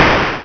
explosion1.wav